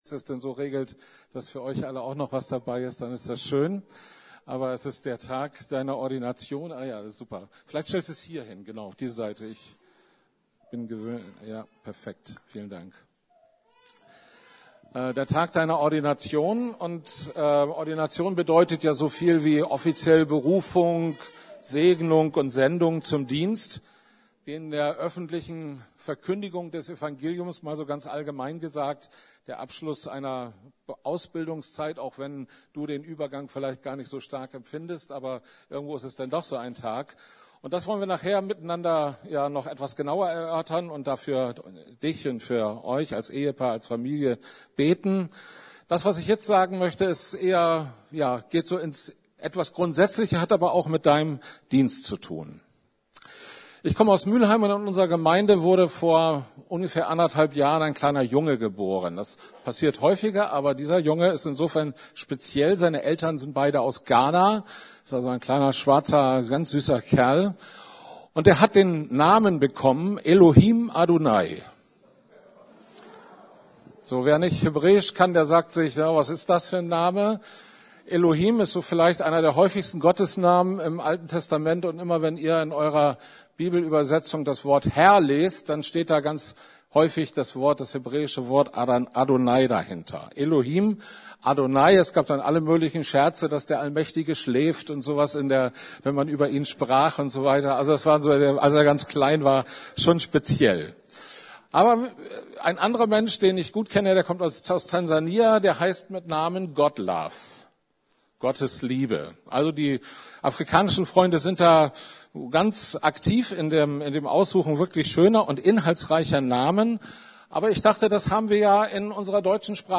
Fürchtegott - von alten Namen und jungen Leuten ~ Predigten der LUKAS GEMEINDE Podcast